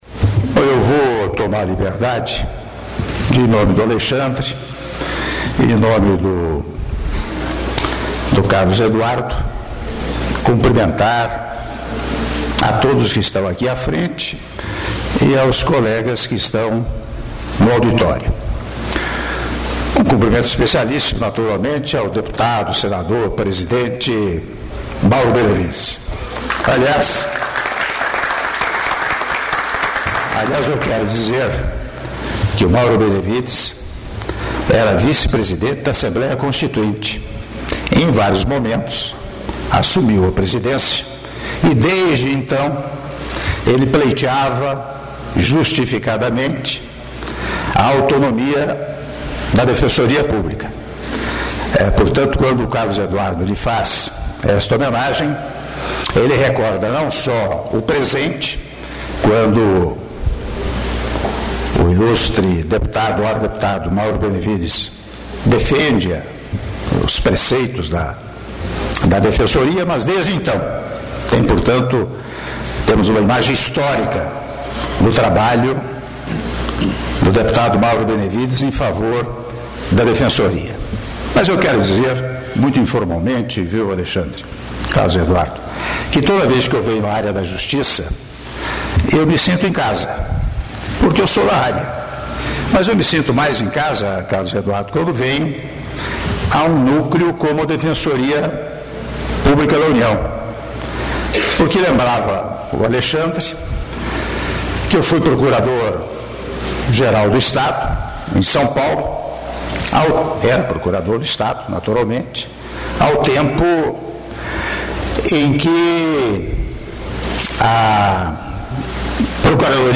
Áudio do discurso do presidente da República em exercício, Michel Temer, durante a posse de Carlos Eduardo Paz no cargo de Defensor Público-Geral - Ministério da Justiça/DF (6min23s)